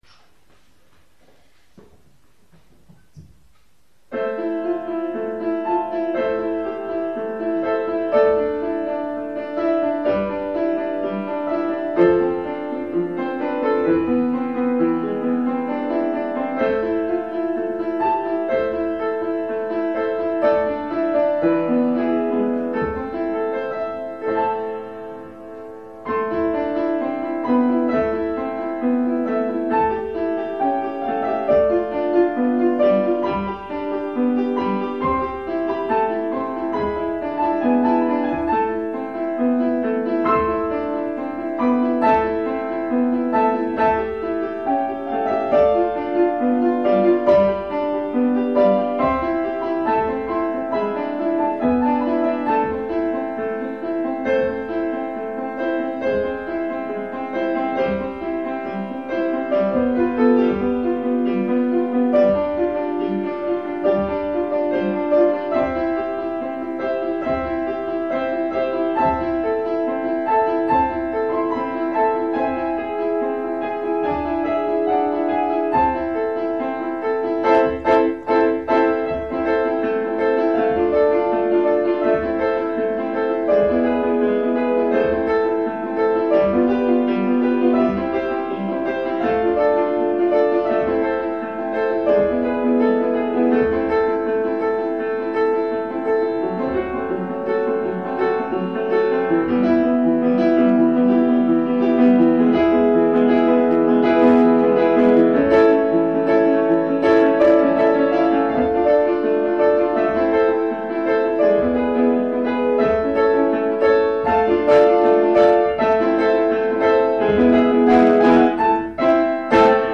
האיכות בינונית, אבל ניכר שאתה שולט בפסנתר היטב.
הפיתוח של המנגינה מאוד יפה, וגם המעבר מנושא לנושא.